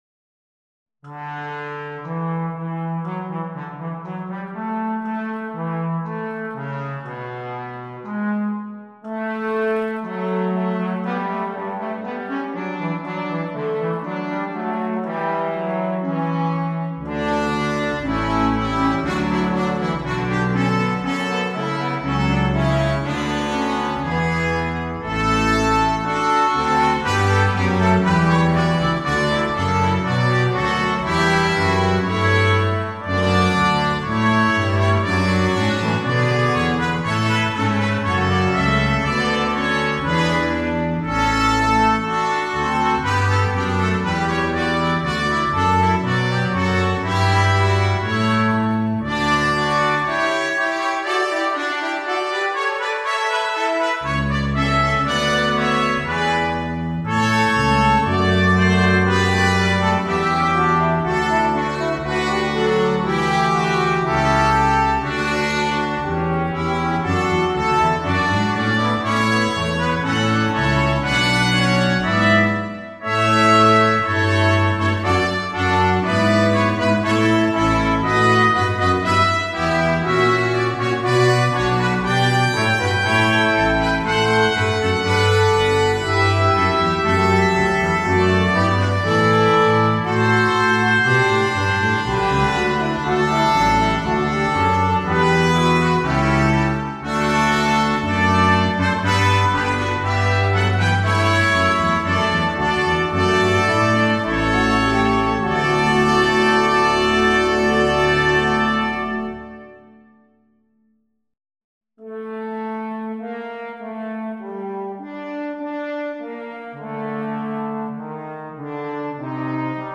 Quintet
sans instrument solo
Classique
Partie 1: Trompette sib, Cornet à Pistons sib
Partie 3: Cor d’harmonie
Partie 4: Trombone – Clé de fa
Partie 5: Tuba